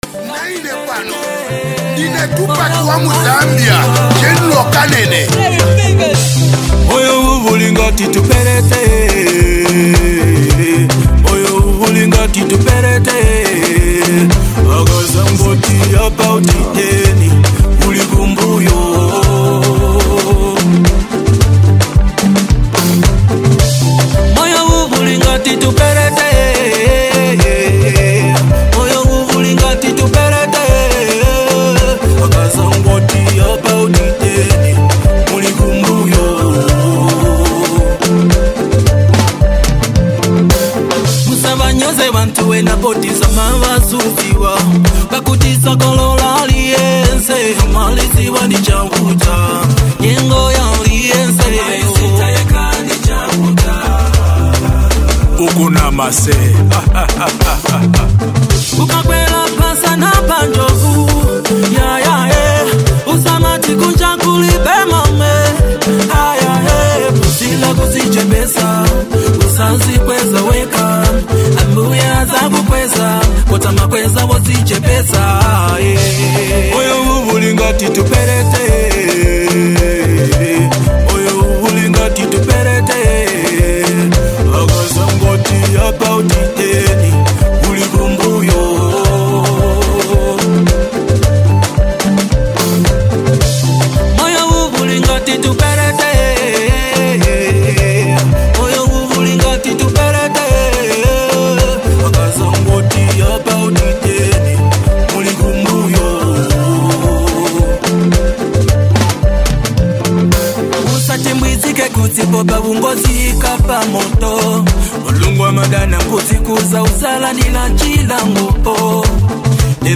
heartfelt melody